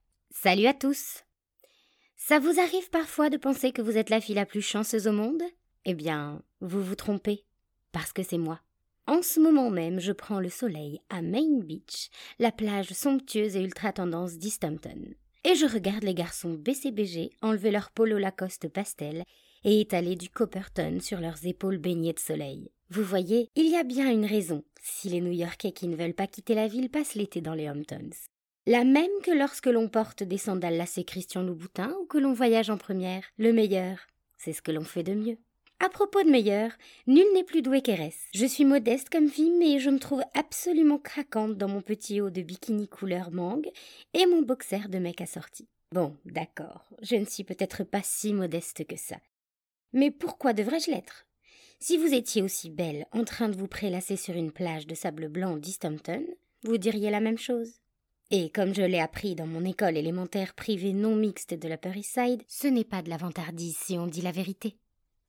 démo livre audio - extrait gossip girl
5 - 35 ans - Mezzo-soprano